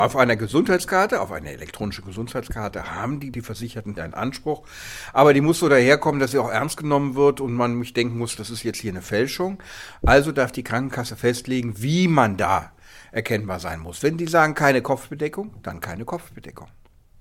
O-Ton: Keine elektronische Gesundheitskarte für den Weihnachtsmann